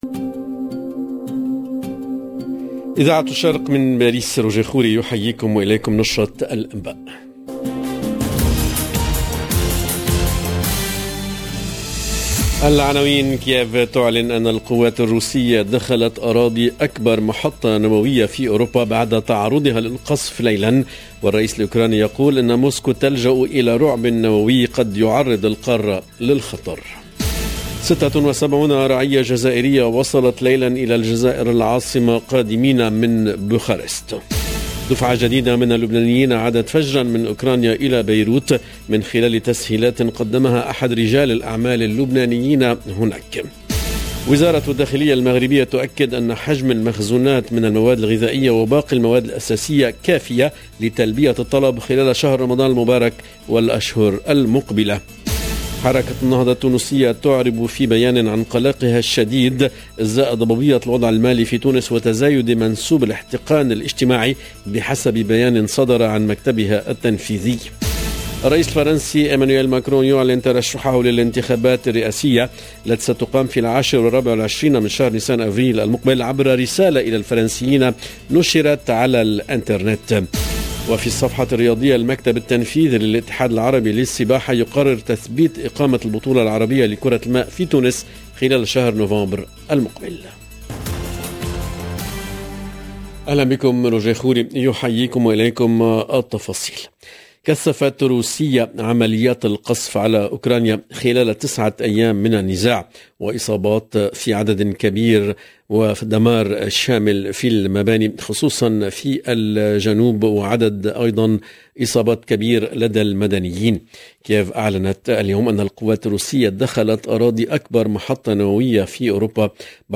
LE JOURNAL EN LANGUE ARABE DE LA MI-JOURNEE DU 4/03/22